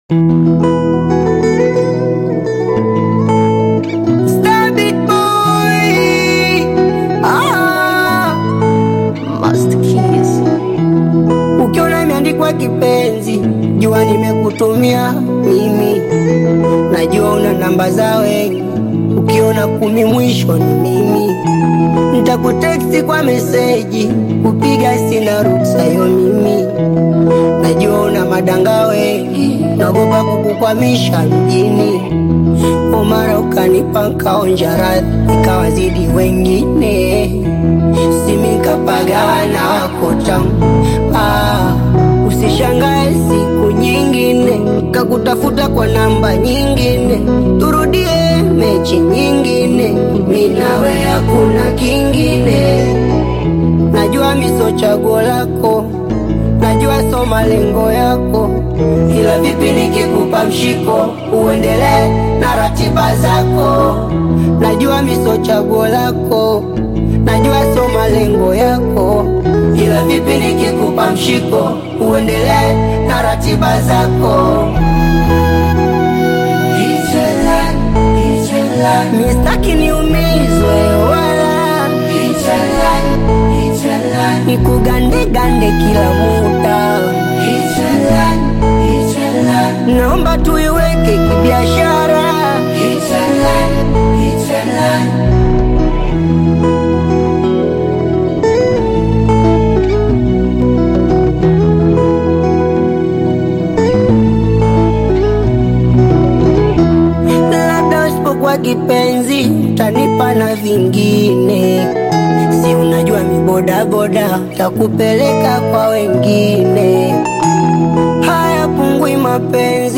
R&B/Pop
American boy band
polished production and melodic hooks